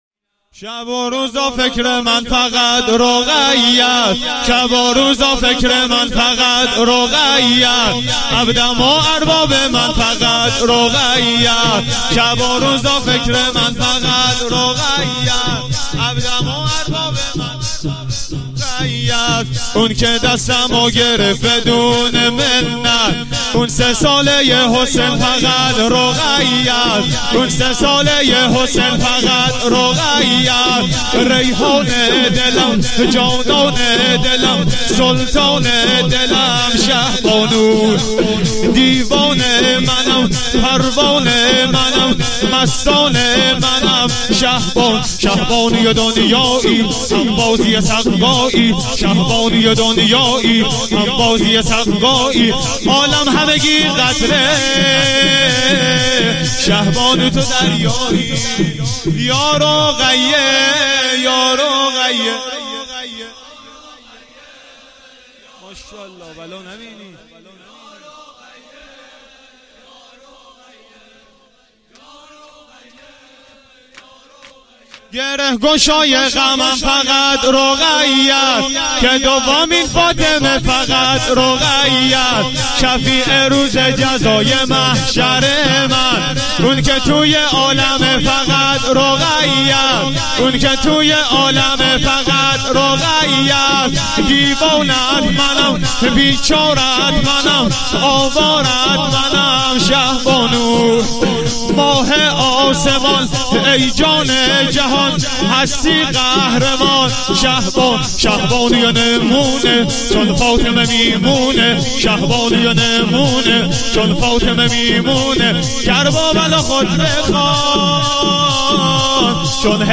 شور2
شب سوم دهه دوم محرم